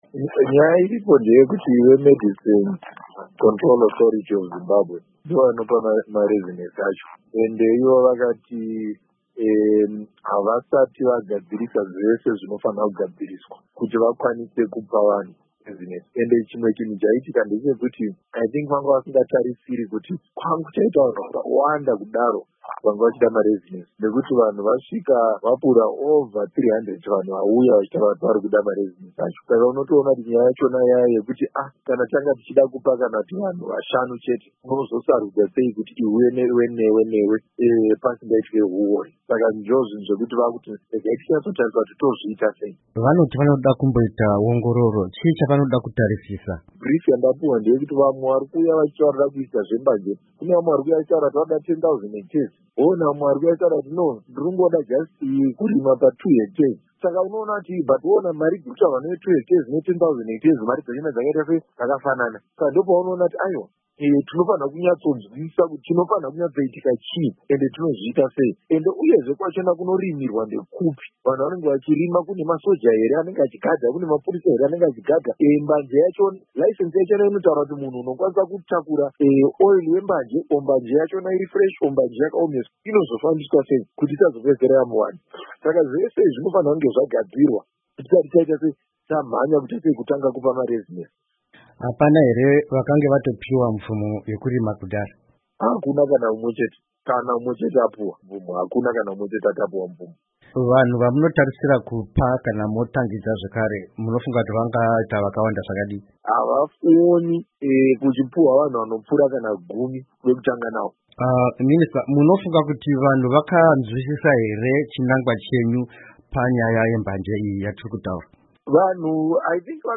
Hurukuro naVaTerrance Mukupe